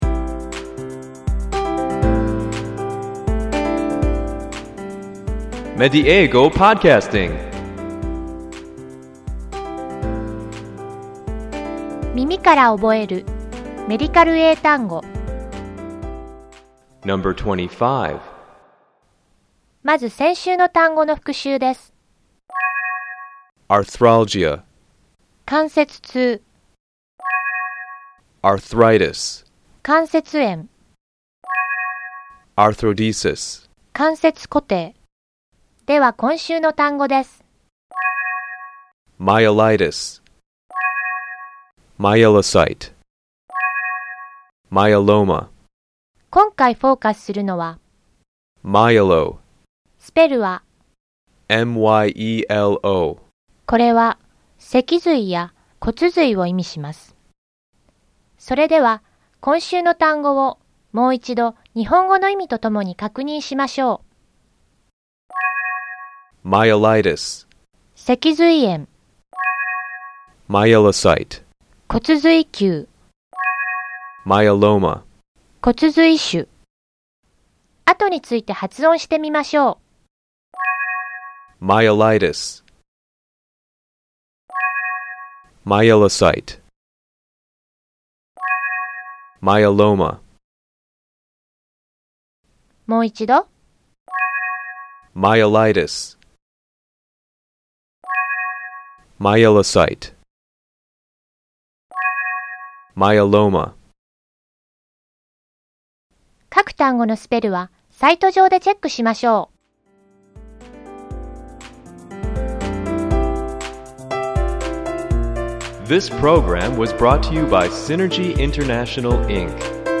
ネイティブの発音を聞いて，何度も声に出して覚えましょう。